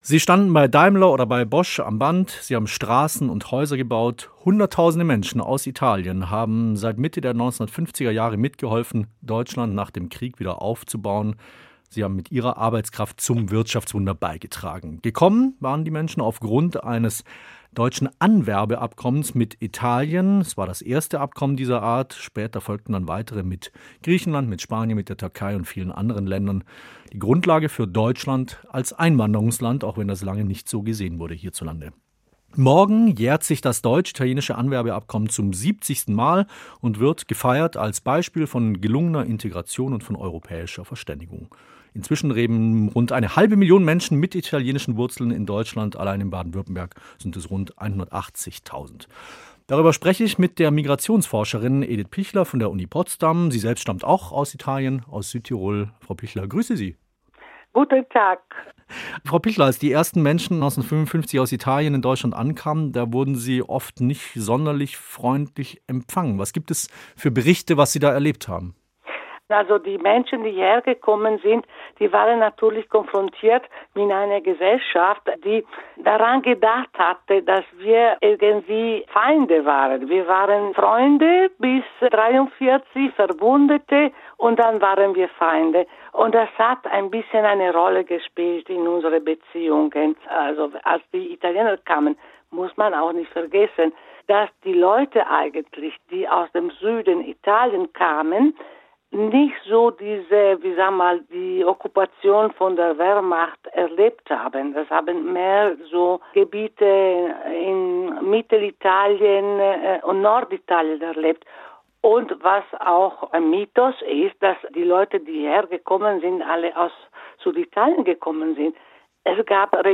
Gespräch
Interview mit